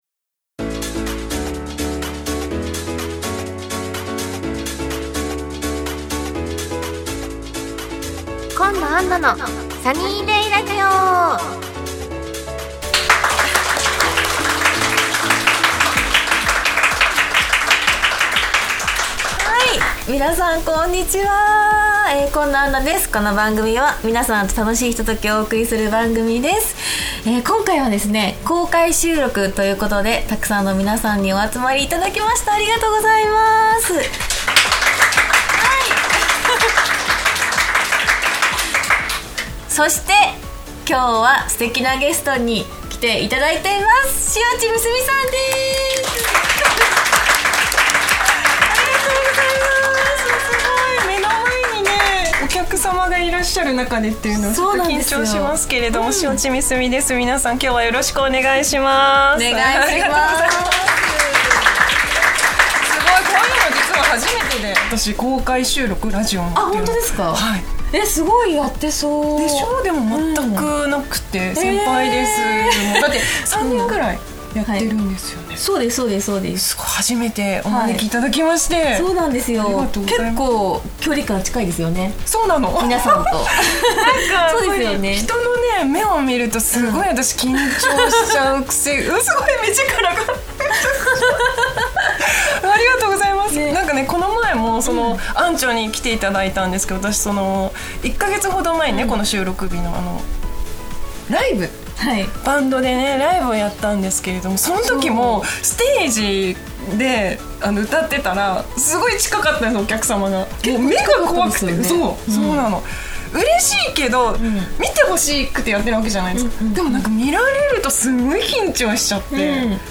今年も公開収録の季節がやってきました！今回のスペシャルゲストは塩地美澄さん♪おふたりのコンビが久しぶりの復活です！